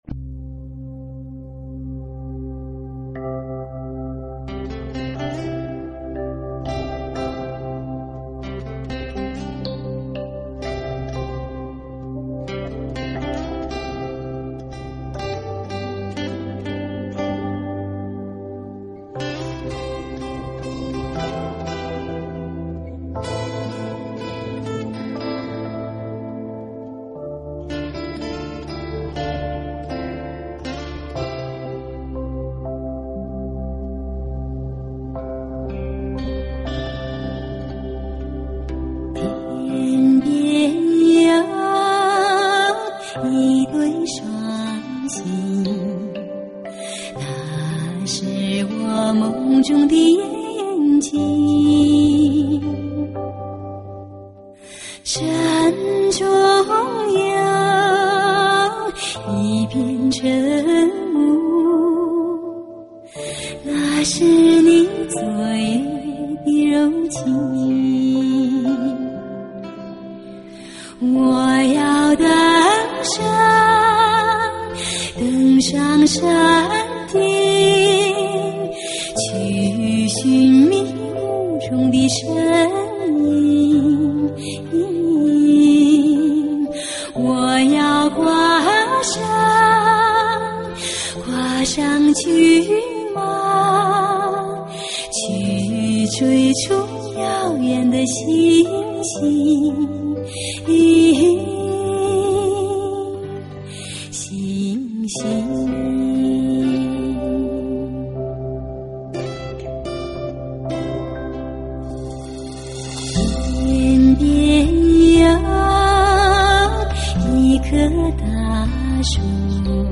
类型: HIFI试音
试听(低品质）